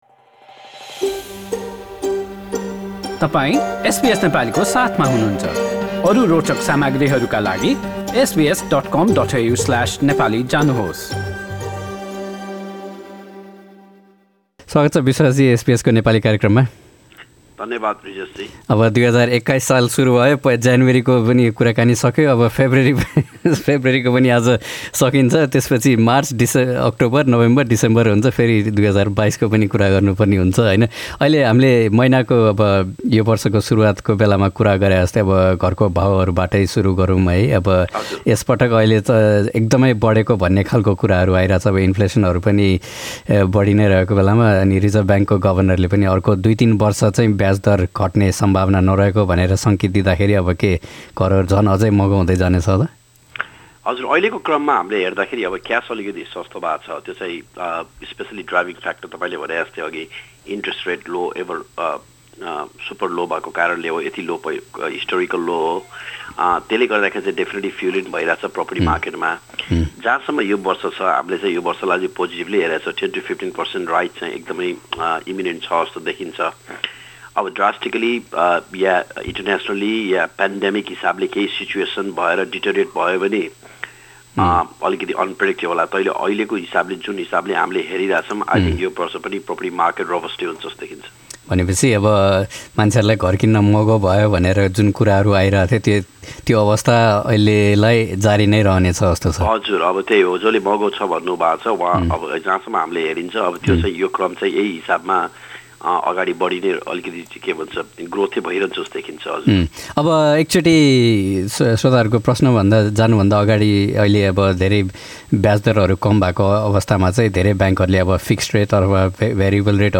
एसबीएस नेपाली पोडकास्ट
आर्थिक कुराकानी हरेक महिनाको पहिलो आइतवार अपराह्न ४ बजेको कार्यक्रममा प्रत्यक्ष प्रसारण हुन्छ।